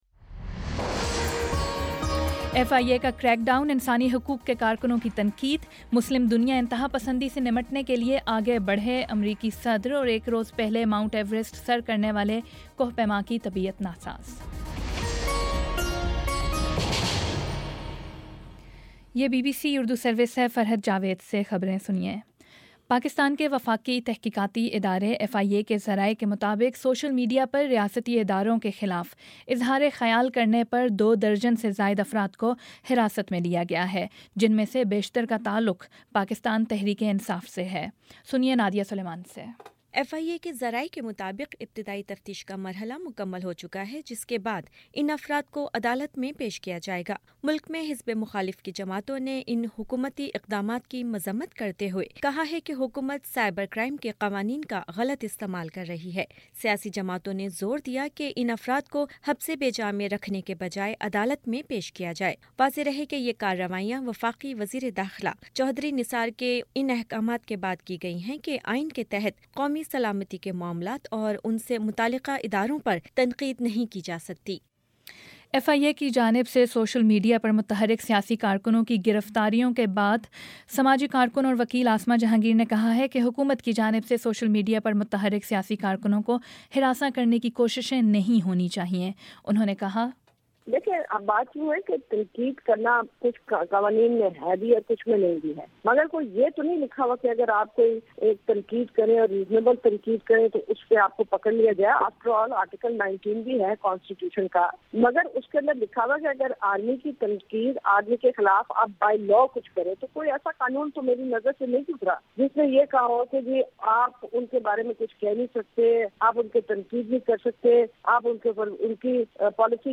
مئی 22 : شام پانچ بجے کا نیوز بُلیٹن